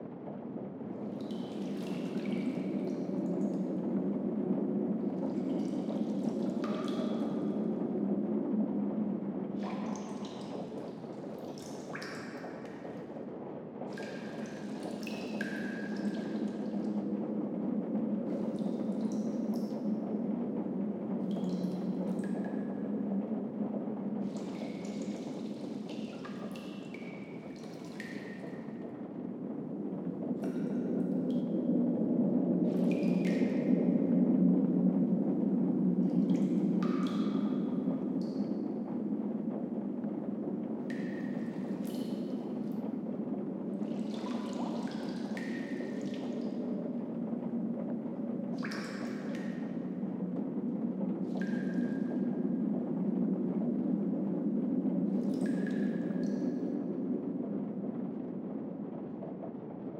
BGS Loops
Cave Rain.ogg